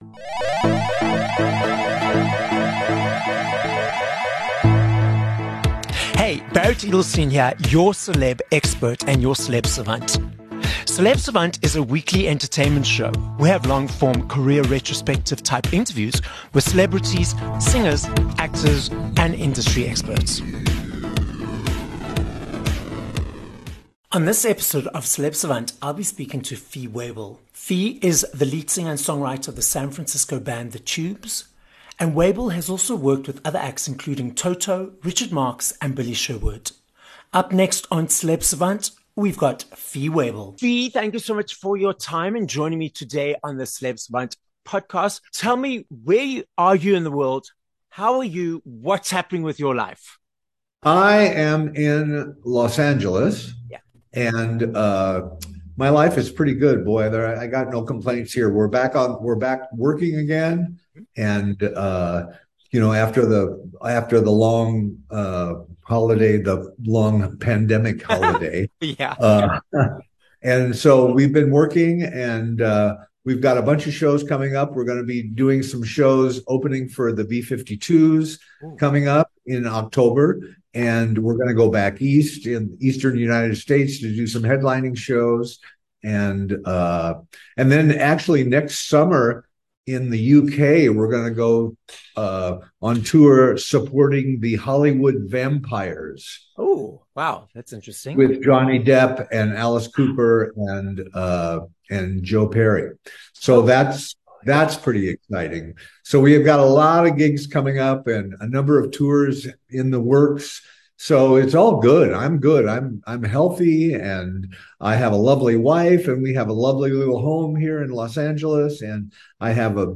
19 Dec Interview with Fee Waybill (from The Tubes)